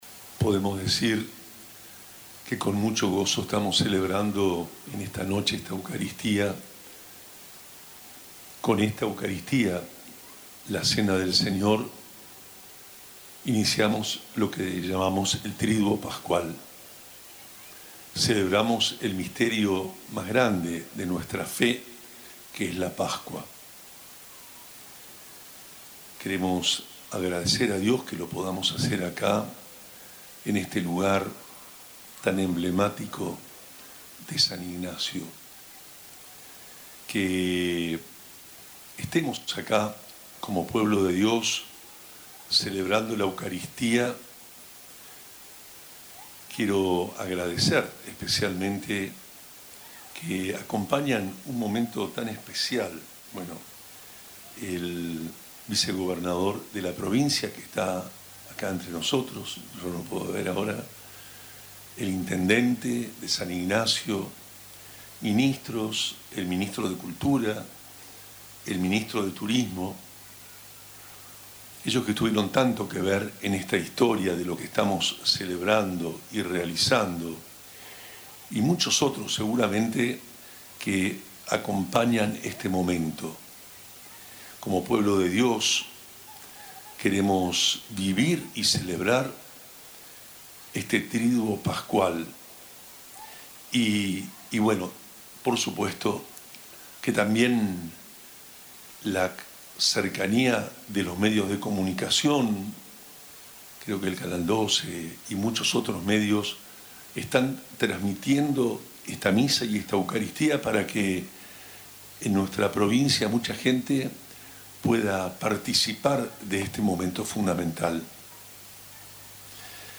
El jueves por la noche, la emblemática Plaza de la Reducción Jesuítica de San Ignacio Miní se llenó de devoción, historia y cultura con la celebración de la Misa Popular de las Misiones. La ceremonia, que congregó a miles de fieles y visitantes, constituyó un evento trascendental que integró lo religioso y lo cultural.
homilia-monse-jueves-santo.mp3